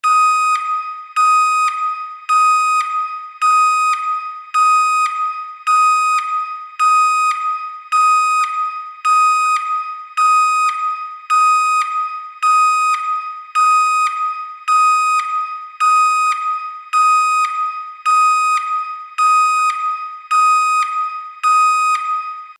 Звуки сигнала тревоги
На этой странице собрана коллекция звуков сигналов тревоги разной длительности и тональности.